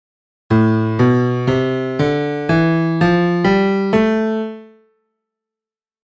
a-aeolian-mode.mp3